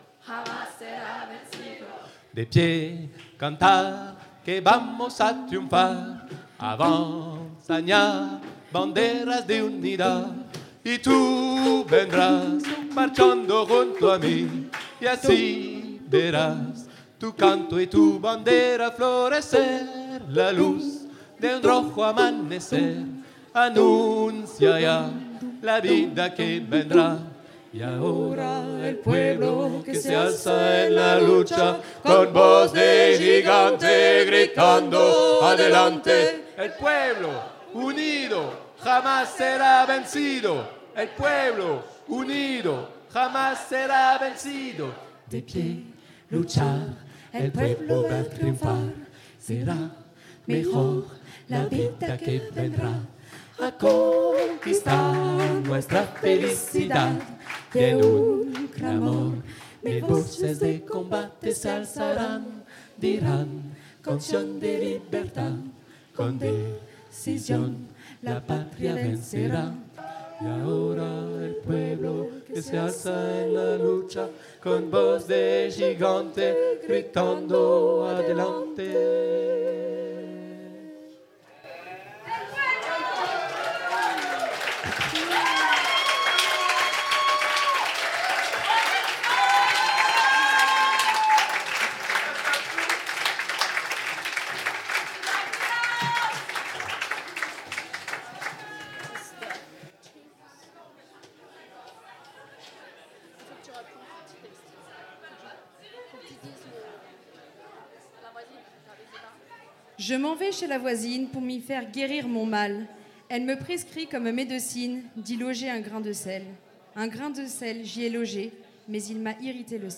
La voix est au cœur des musiques à danser de ces initiatives, mais d’autres instruments peuvent bien sûr être utilisés…
Chorale de chants à danser polyphoniques rêvolutionnaires
Un groupe d’une dizaine de chanteureuses, à géométrie variable, avec une énergie contagieuse !